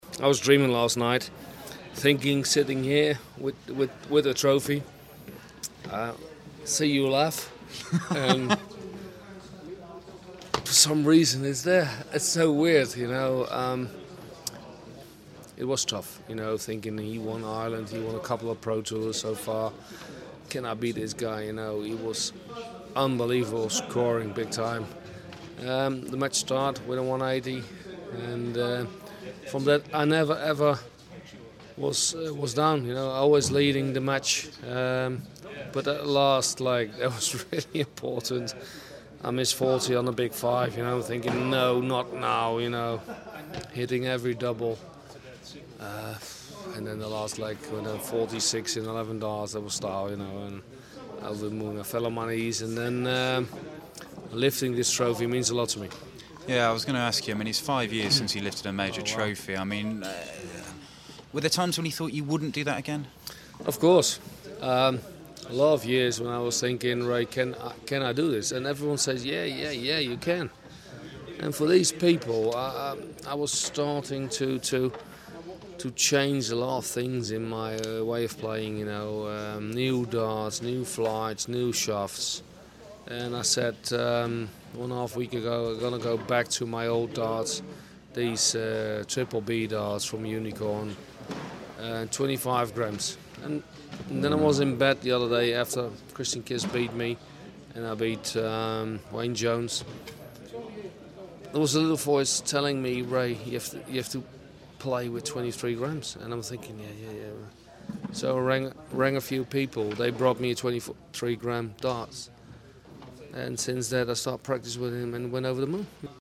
William Hill GSOD - Barney Interview Part 1 (Final)